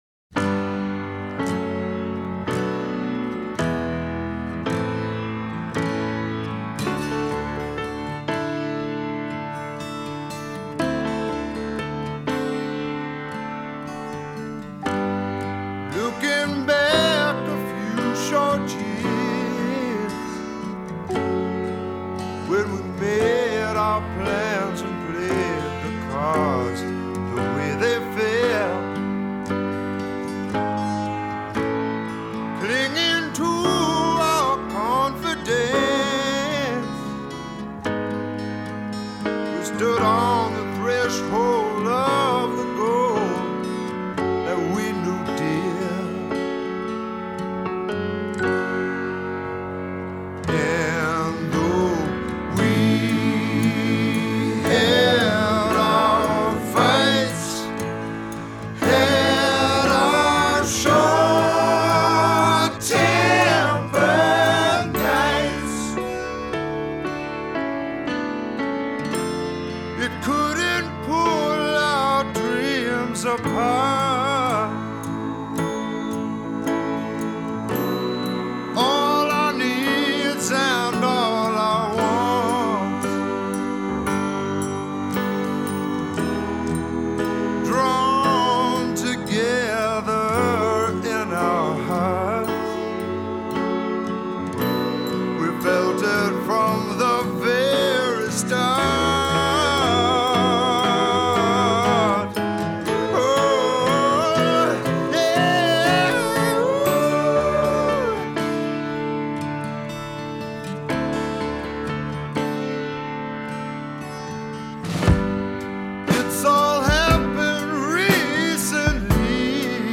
Медляки